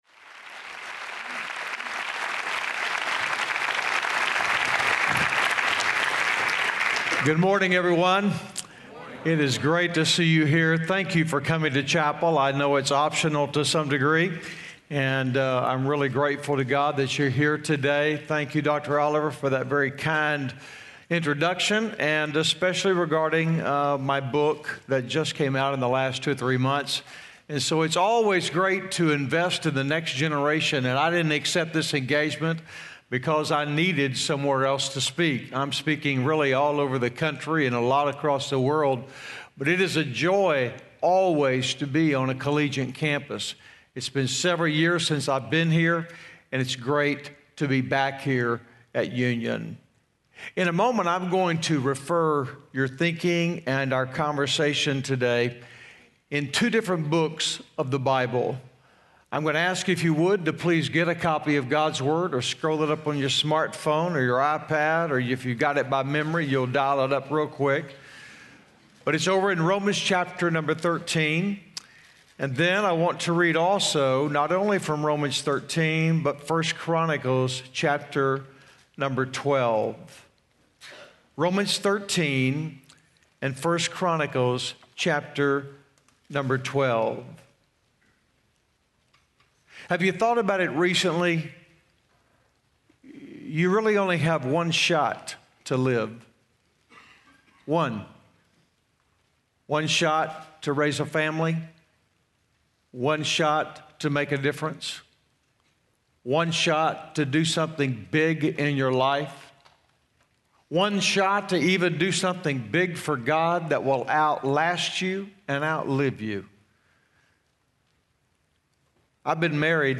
Chapel: Ronnie Floyd
Ronnie Floyd , President, Southern Baptist Convention